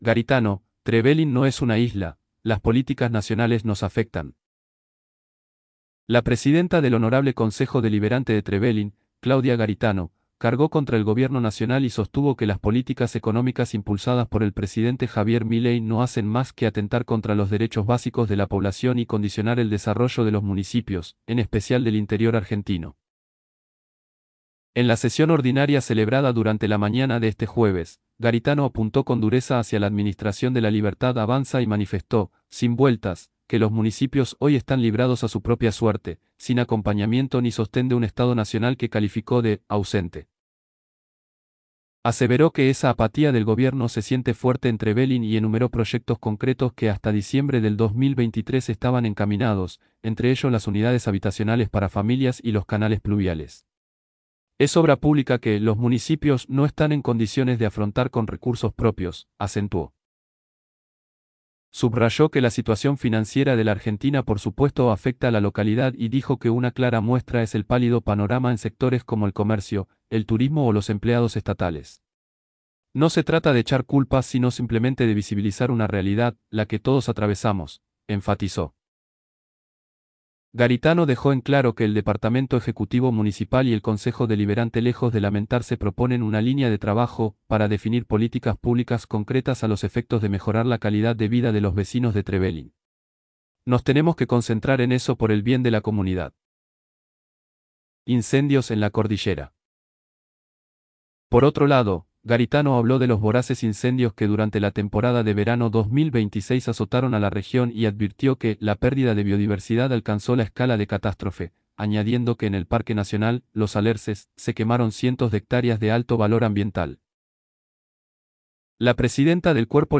La presidenta del Honorable Concejo Deliberante de Trevelin, Claudia Garitano, cargó contra el gobierno nacional y sostuvo que las políticas económicas impulsadas por el presidente Javier Milei no hacen más que atentar contra los derechos básicos de la población y condicionar el desarrollo de los municipios, en especial del interior argentino. En la sesión ordinaria celebrada durante la mañana de este jueves, Garitano apuntó con dureza hacia la Administración de la Libertad Avanza y manifestó, sin vueltas, que los municipios hoy están librados a su propia suerte, sin acompañamiento ni sostén de un Estado nacional que calificó de “ausente”.